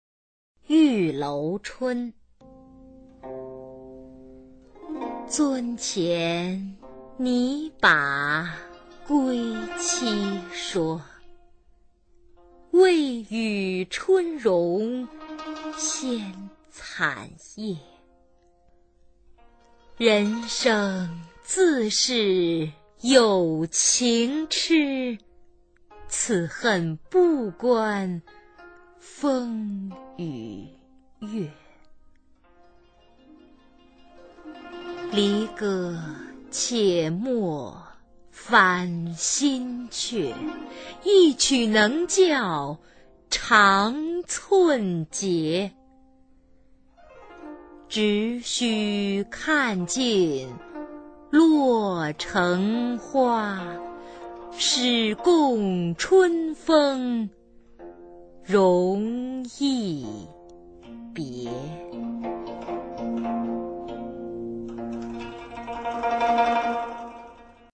[宋代诗词朗诵]欧阳修-玉楼春 古诗词诵读